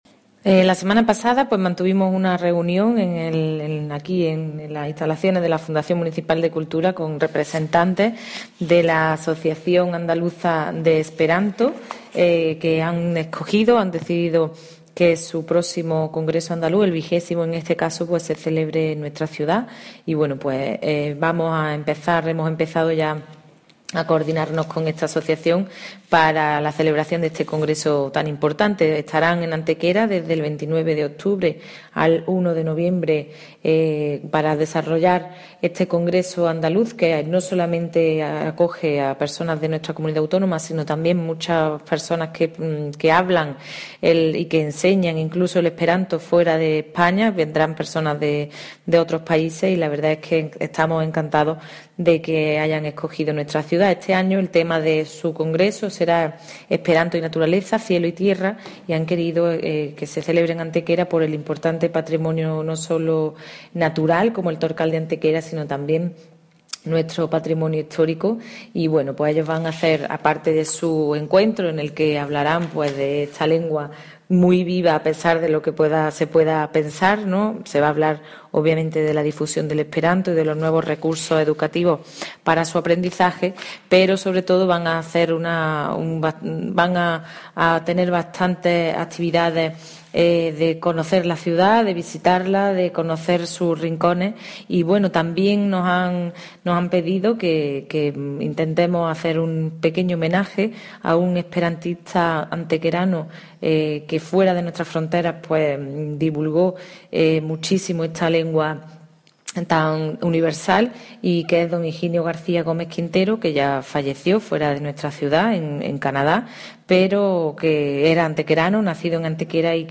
Cortes de voz A. Cebrián 1144.71 kb Formato: mp3